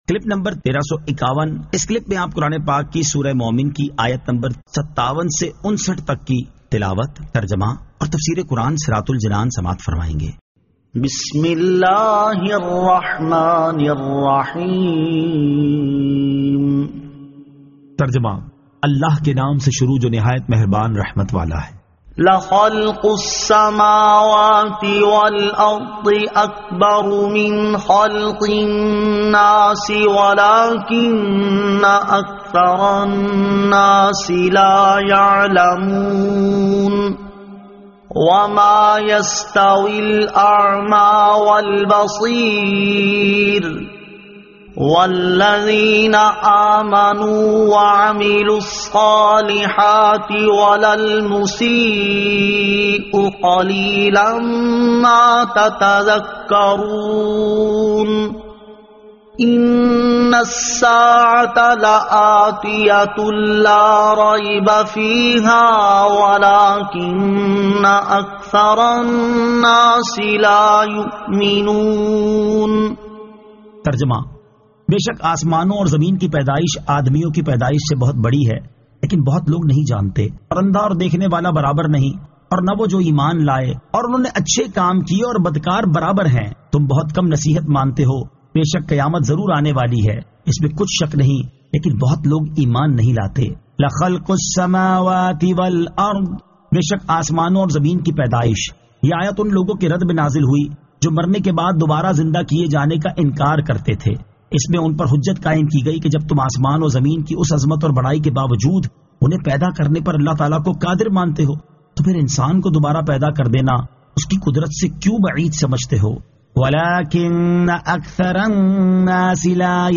Surah Al-Mu'min 57 To 59 Tilawat , Tarjama , Tafseer